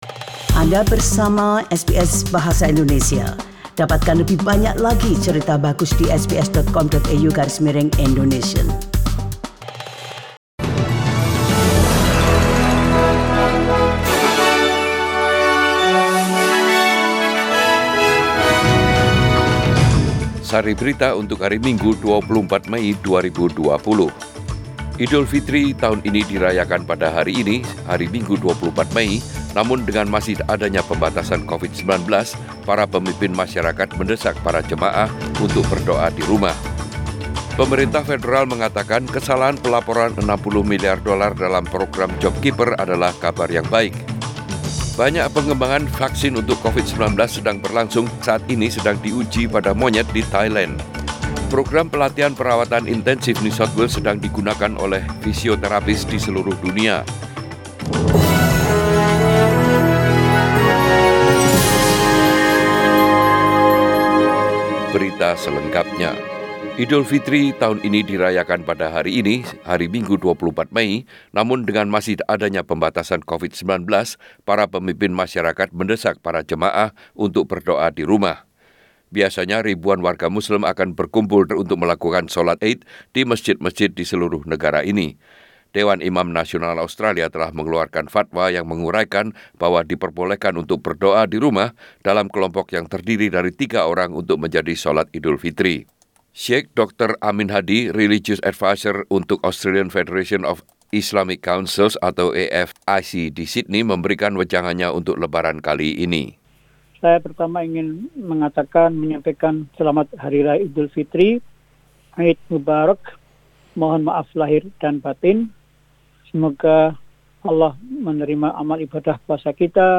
Warta Berita Radio SBS Program bahasa Indonesia - 24 Mei 2020